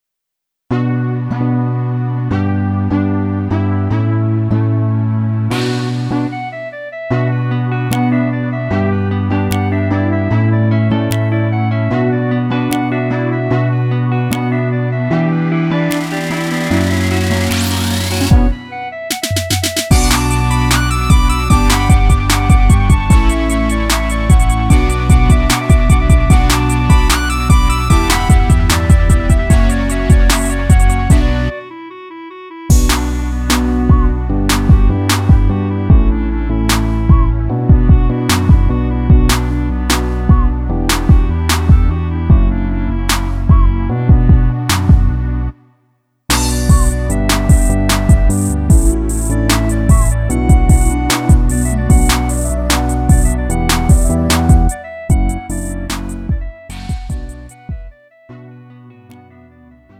음정 원키 3:27
장르 구분 Lite MR